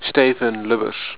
Pronounced